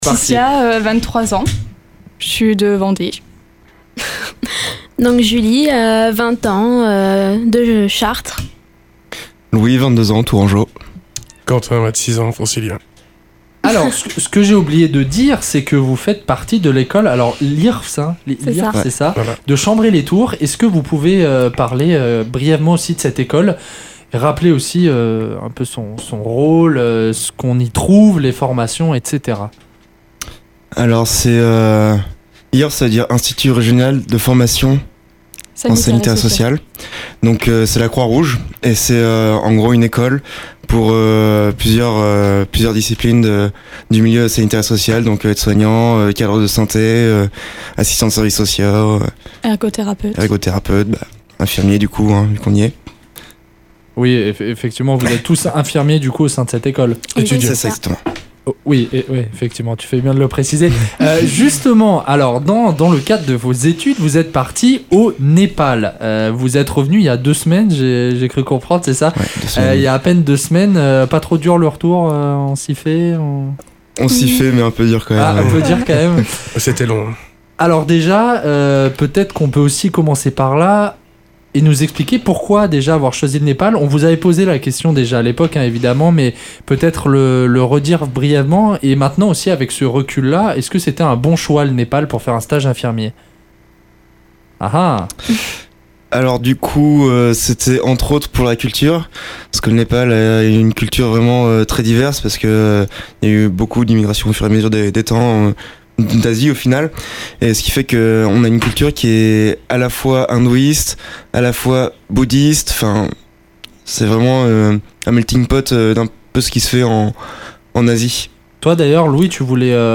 Ce soir dans Sortez! nous étions en compagnie de quatre étudiants infirmiers à l’IRSS de Chambray-lès-Tours pour évoquer leur stage de 5 semaines fait au Népal ! Interview.